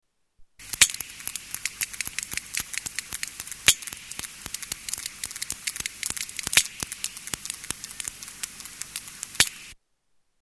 Sperm whale echolocation
Category: Animals/Nature   Right: Personal